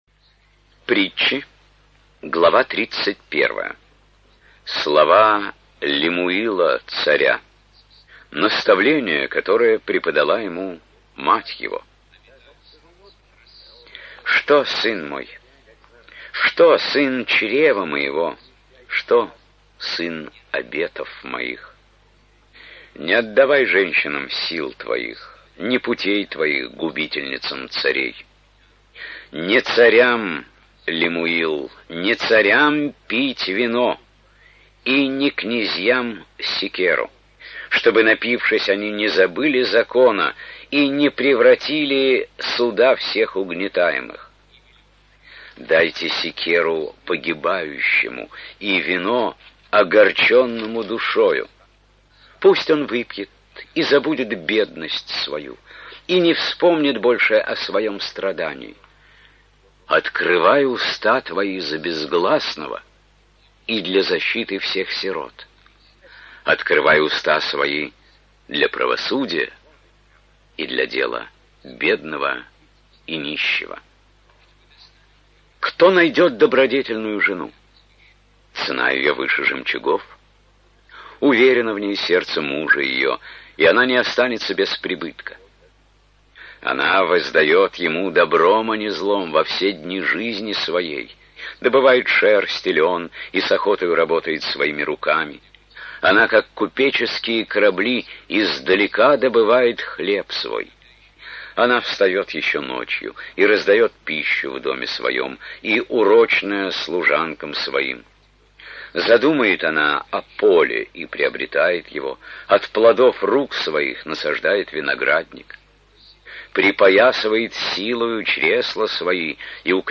Index of /opened/Аудио Библия/Библия. Ветхий и Новый Завет/01 Ветхий Завет/23 Притчей Соломоновых/